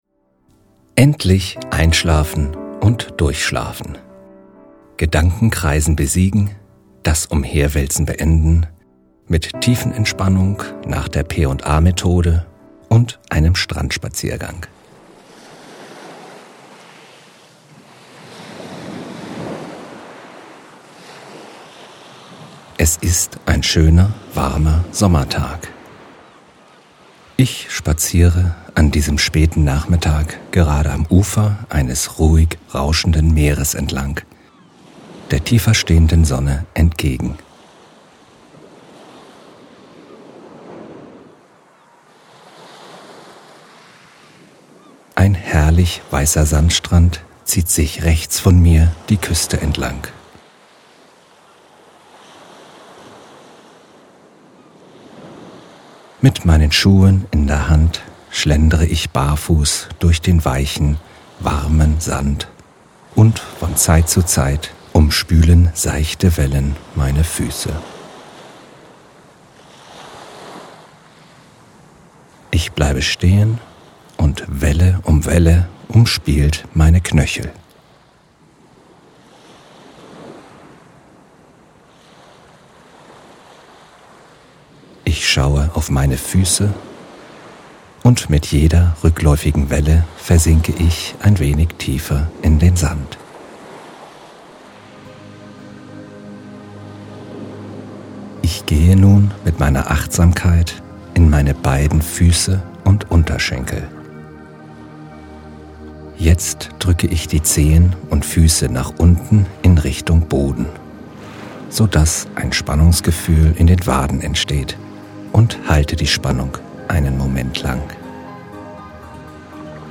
Die ruhige Hintergrundstimmung des Wellenrauschens dieser Einschlafhilfe und eine speziell komponierte 432 HZ Einschlaf-Musik holt Sie emotional ab und intensiviert Ihr Müdigkeitsgefühl.
Der Spreche hat eine für mich sehr beruhigende Stimme, was das hören sehr angenehm macht.
Angenehme Stimme (die Aussprache vielleicht ein wenig zu überbetont) und eine dezente und beruhigende Musik im Hintergrund. Keine unangenehmen Klänge, wie ich sie von manch anderer Entspannungs-CDs kenne.
männliche Stimme